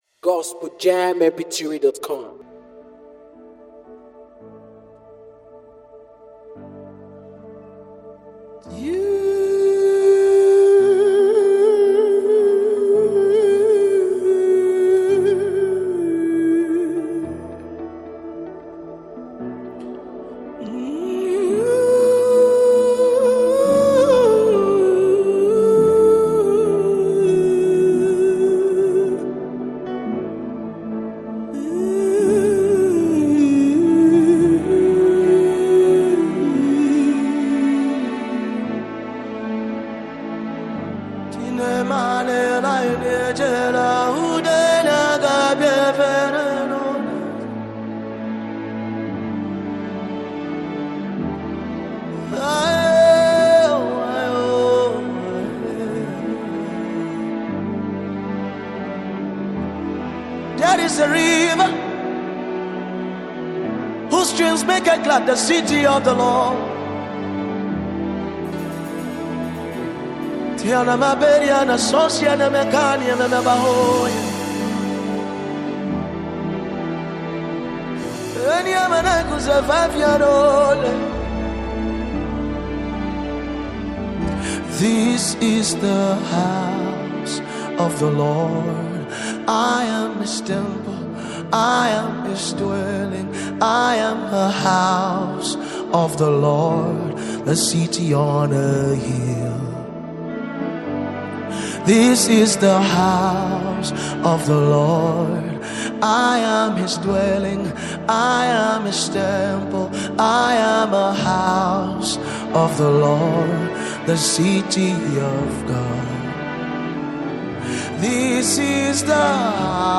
Genre: Deep worship / Prophetic chant.